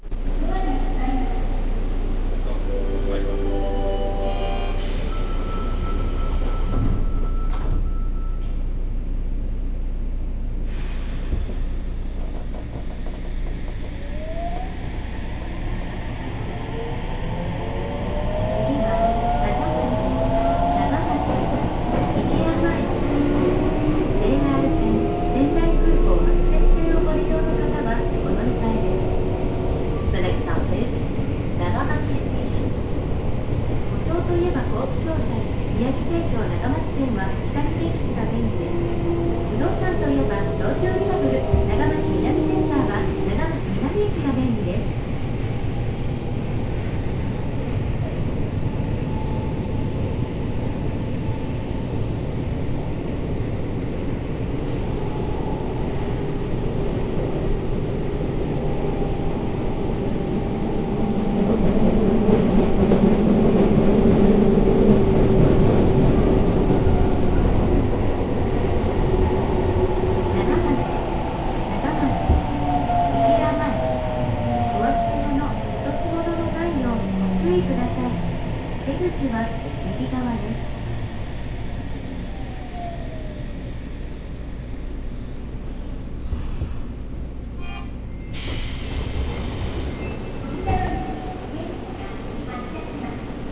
・1000N系走行音
【南北線】長町一丁目→長町（1分33秒：509KB）
VVVFは三菱IGBT。東京メトロの8000系等でも聞く事が出来るモーター音です。車内放送の声も声なので、何だか東京の地下鉄に乗っているような気分になります。
扉の開閉時にブザーが流れるのが、更新前から残る特徴と言えそうです。